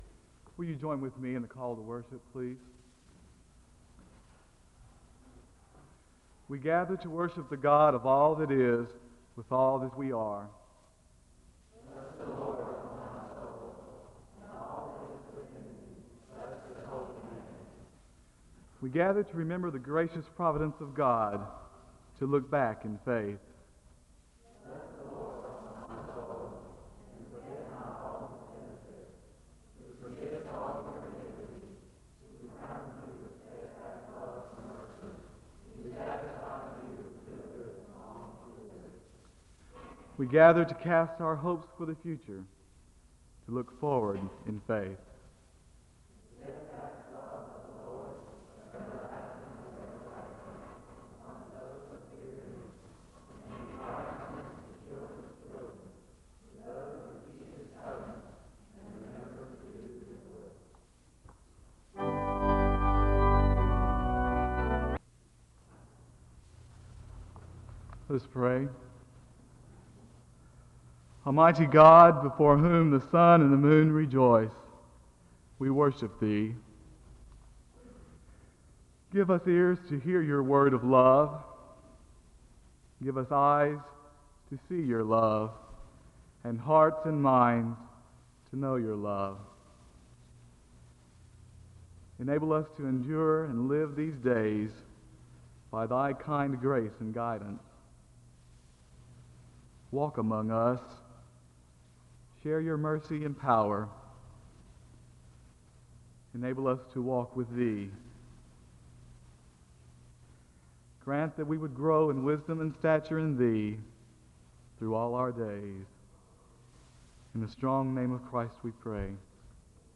The service begins with a responsive reading and a word of prayer (00:00-02:01).
The choir sings a song of worship (04:33-08:48).
The service ends with a benediction (27:20-28:03).